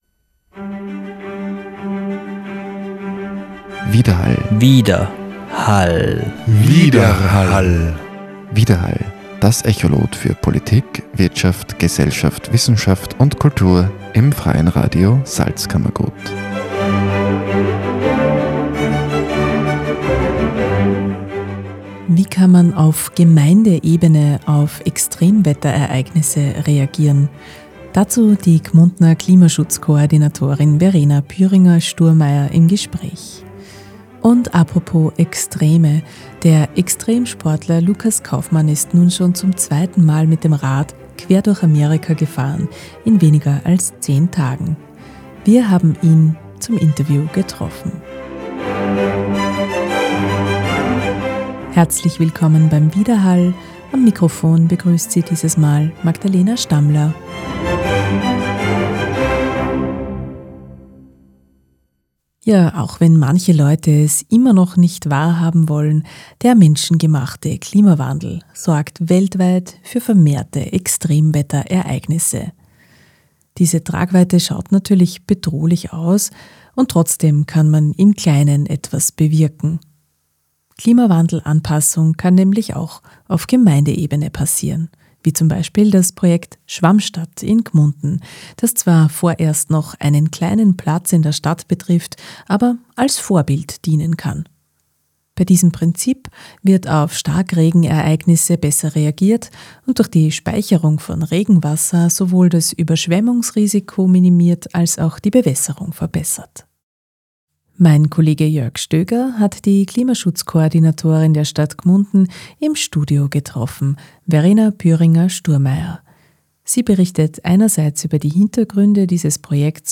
Der Verein Freies Radio Salzkammergut betreibt seit 1999 ein zugangsoffenes Radio und versorgt die drei Bundesländer des Salzkammerguts auf acht Frequenzen mit einem werbefreien Hörfunk-Programm.